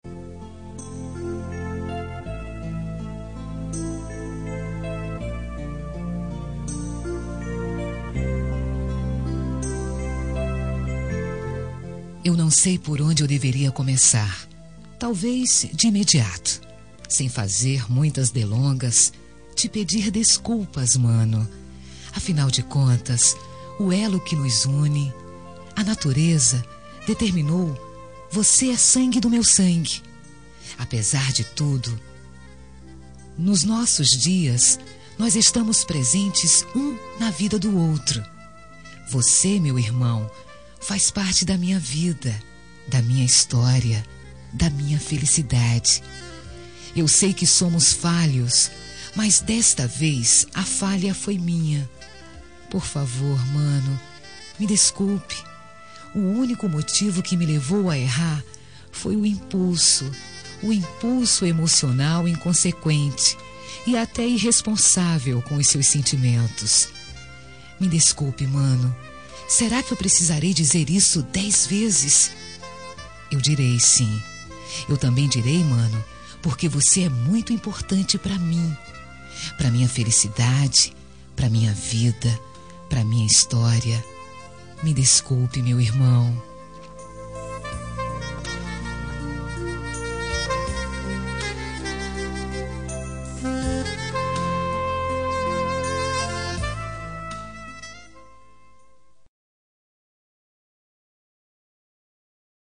Reconciliação Familiar – Voz Feminina – Cód: 088730 – Irmão